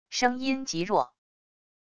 声音极弱wav音频